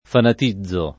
fanatizzare v.; fanatizzo [ fanat &zz o ]